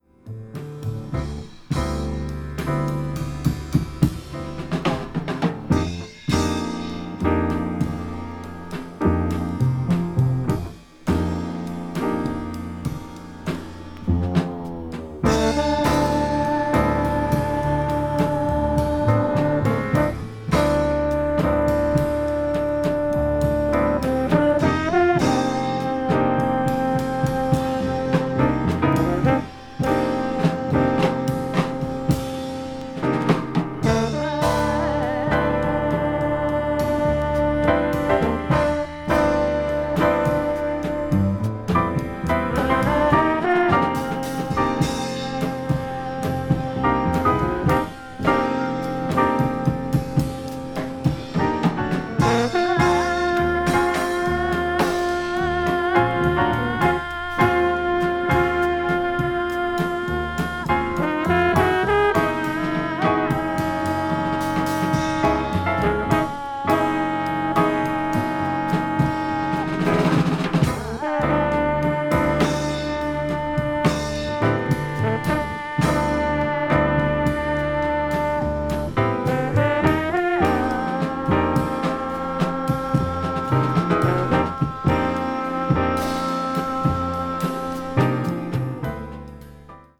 American saxophonist
one-horn trio
bass